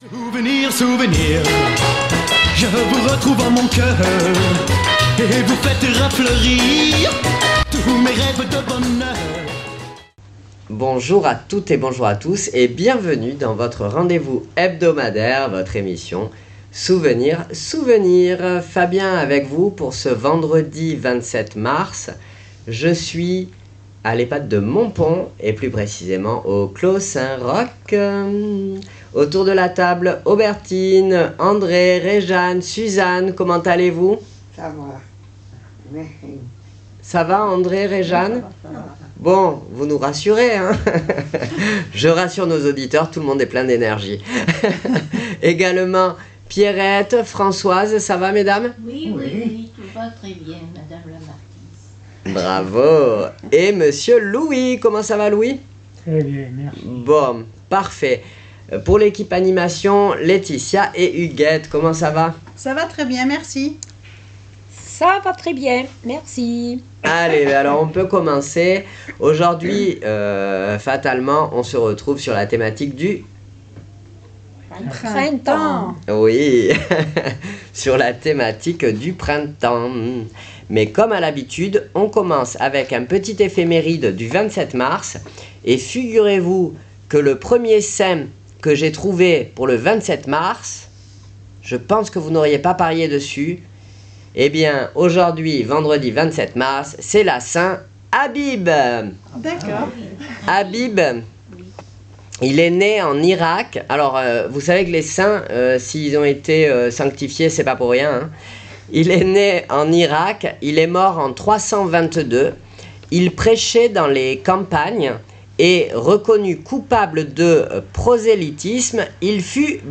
Souvenirs Souvenirs 27.03.26 à l'Ehpad de Montpon " Le printemps "